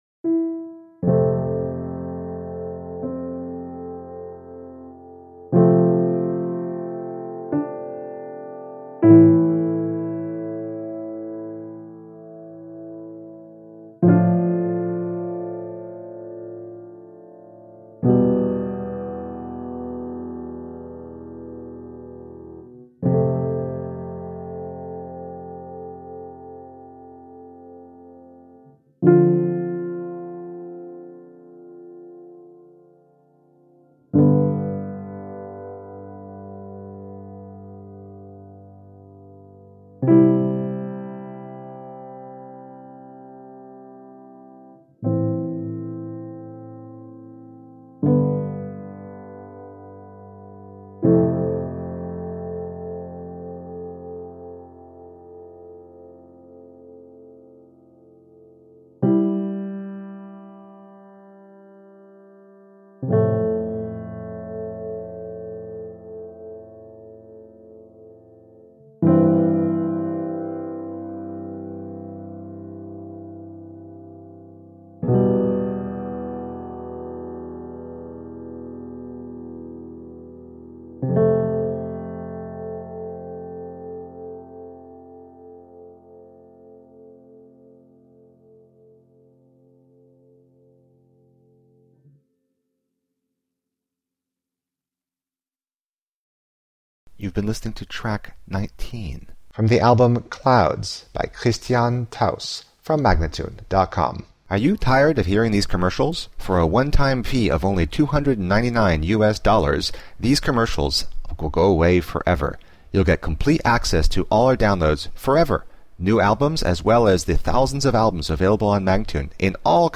Collections of melancholic and minimalistic soundscapes.
A moodful collection of mainly solo piano pieces.